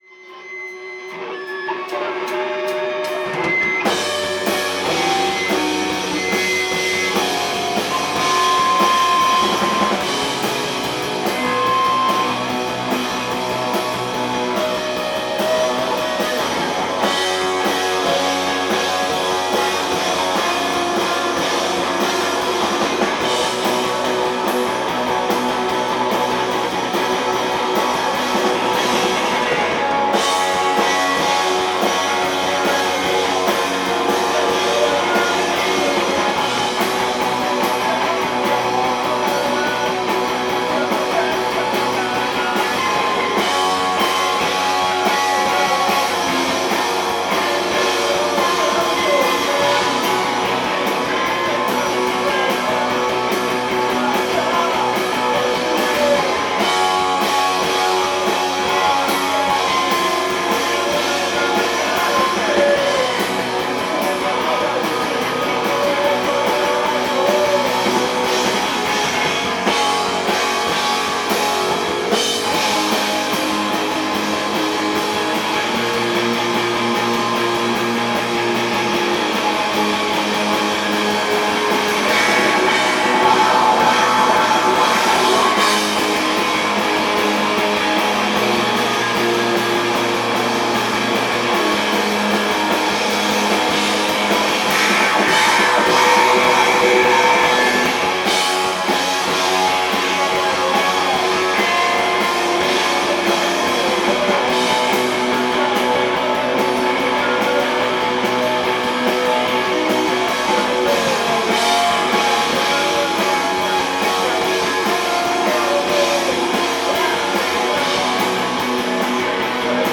live at the Lizard Lounge
Cambridge, MA